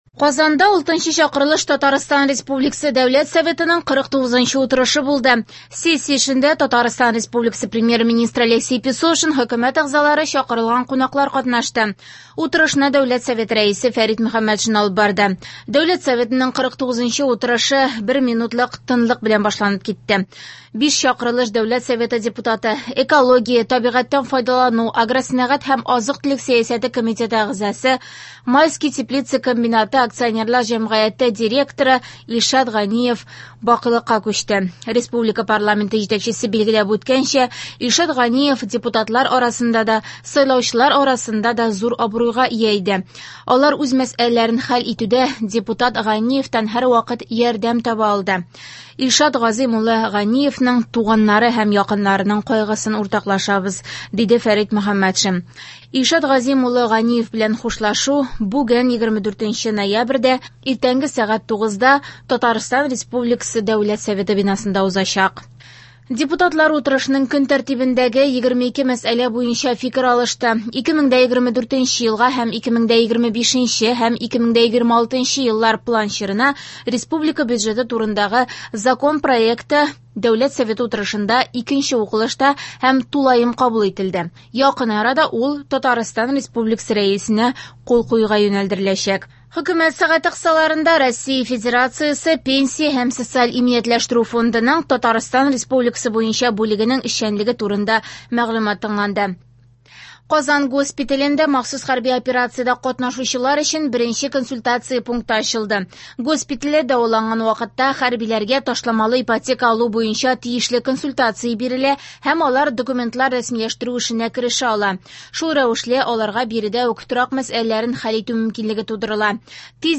Яңалыклар (24.11.23)